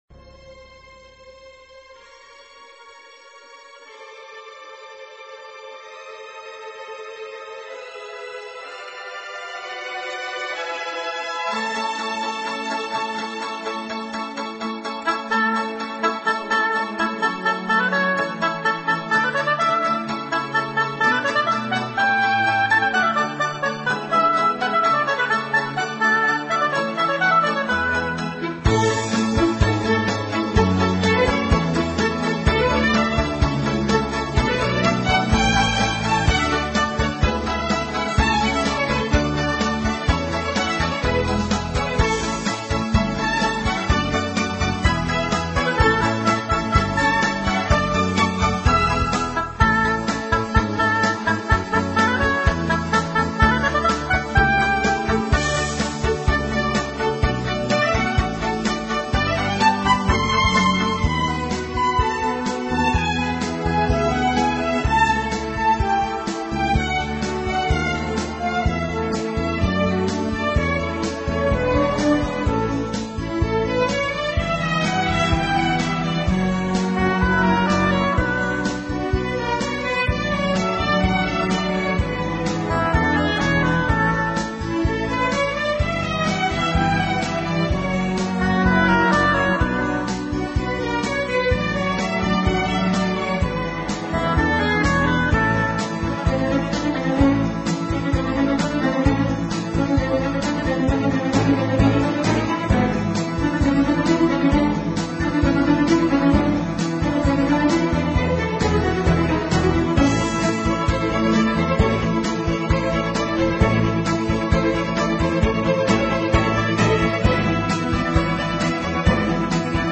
音乐风格：其他|古典|(Neo Classical，室内乐)